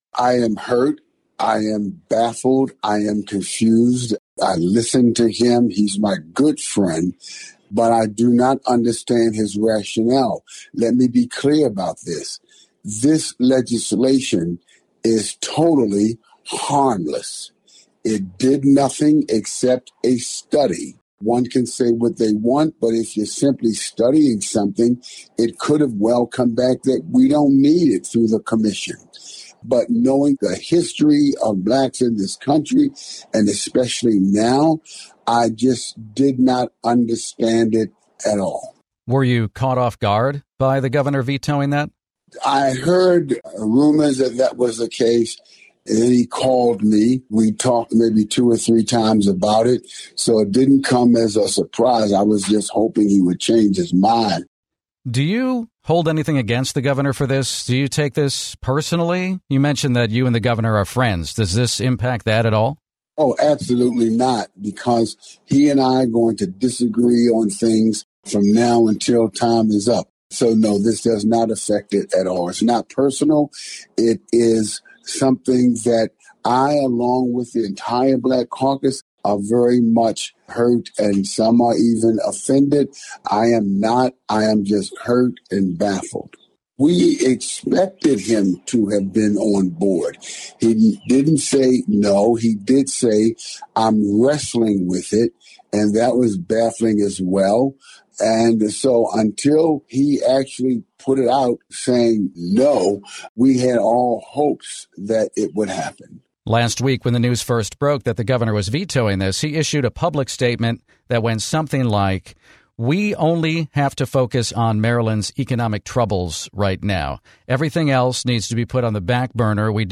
Maryland State Sen. C. Anthony Muse, one of the legislation's sponsors, spoke to WTOP about Gov. Moore's veto.
5-19-muse-interview.mp3